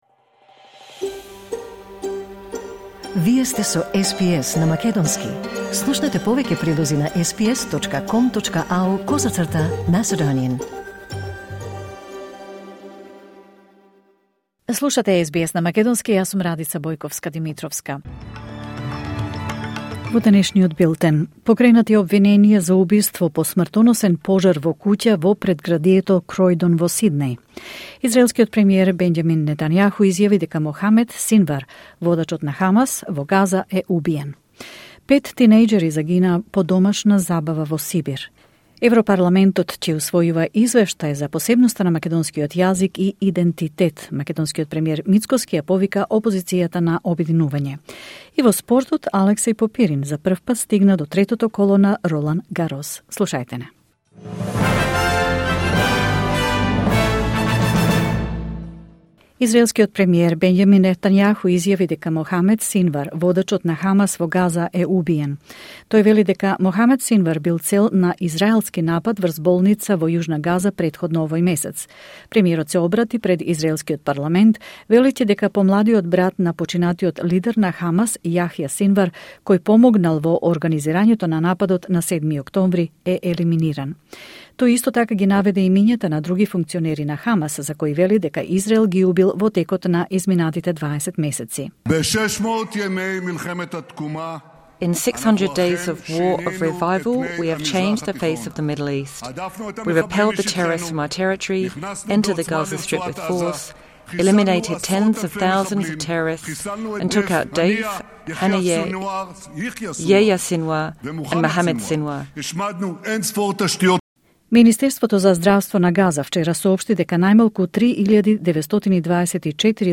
Вести на СБС на македонски 29 мај 2025